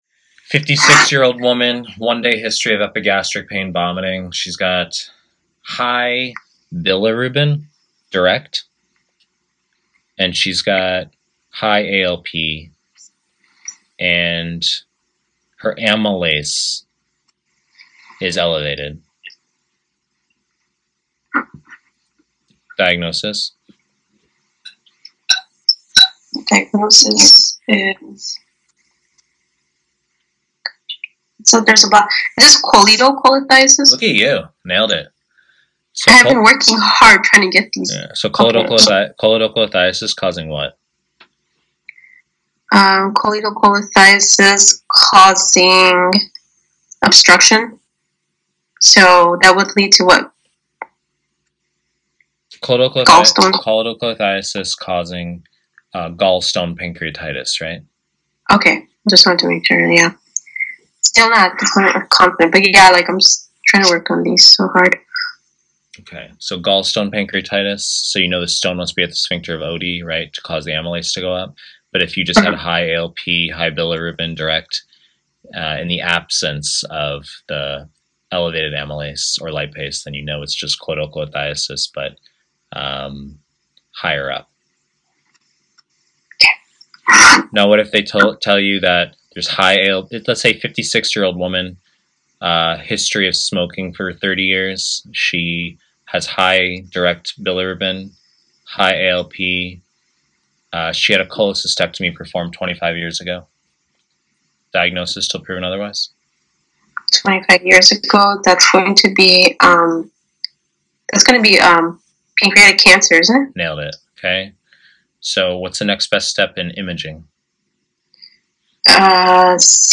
Pre-recorded lectures